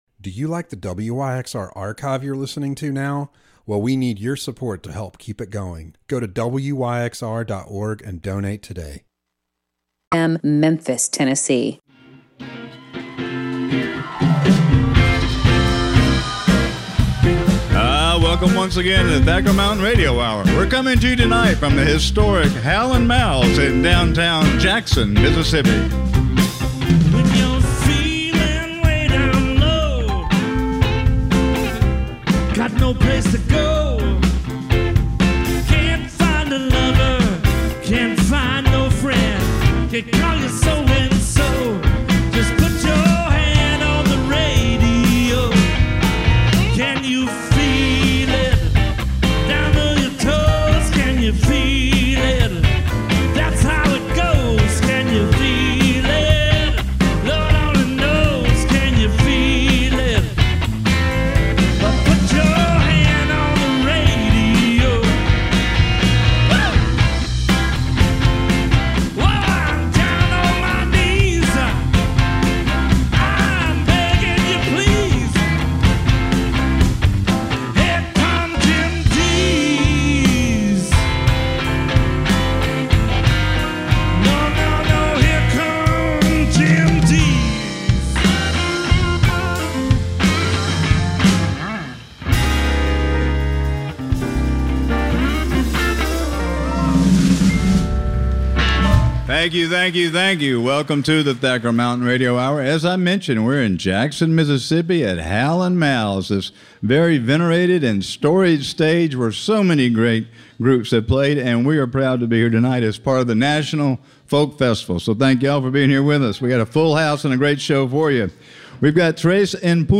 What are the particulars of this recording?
Live from Oxford, Mississippi